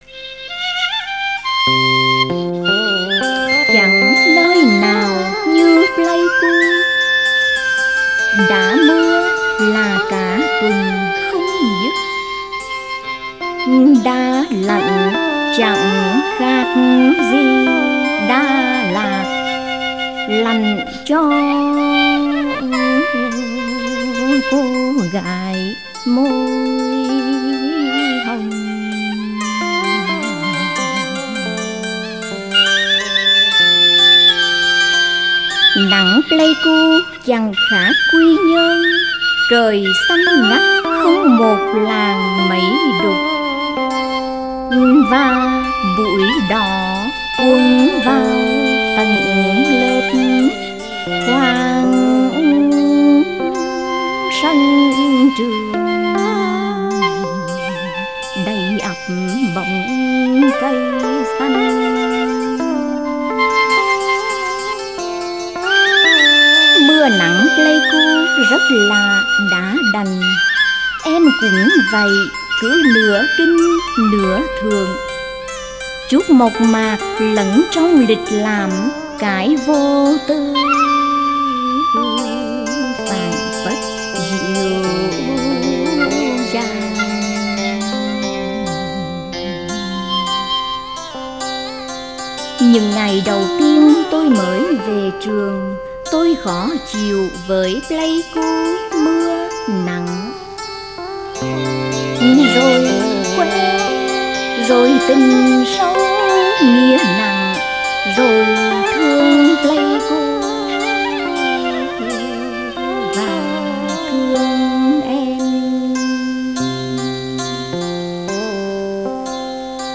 Ngâm